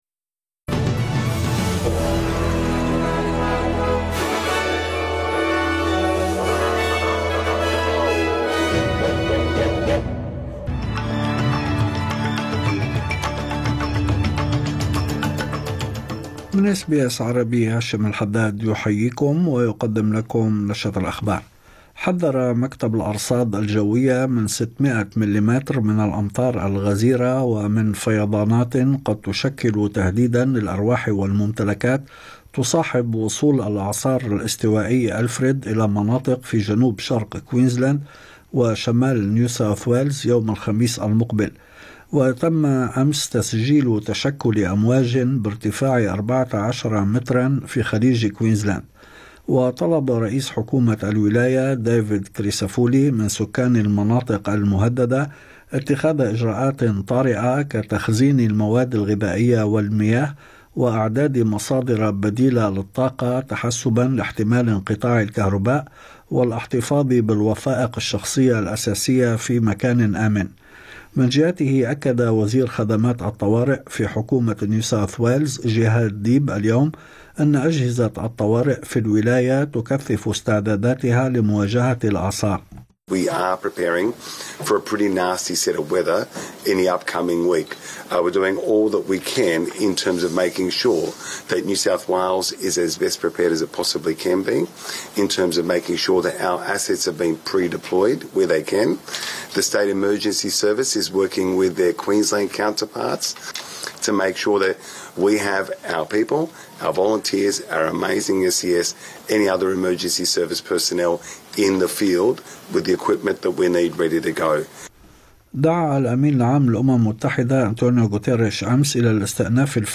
نشرة أخبار الظهيرة 3/3/2025